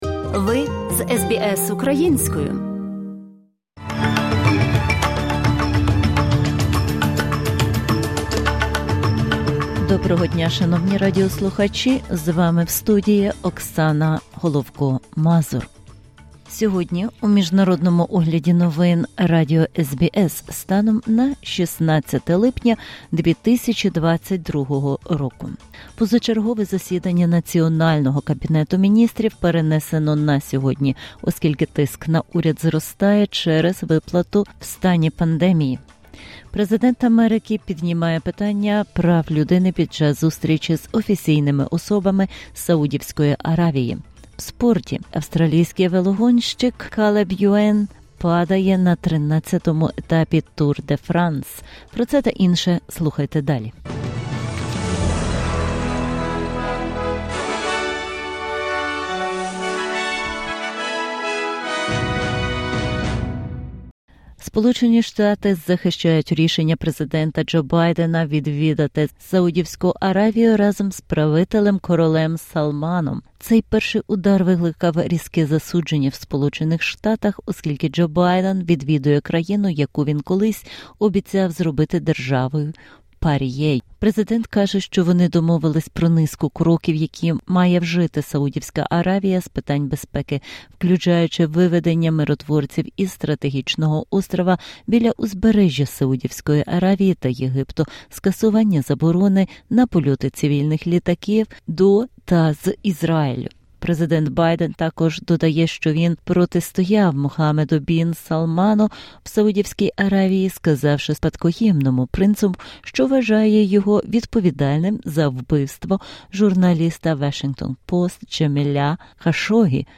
SBS новини українською - 16/07/2022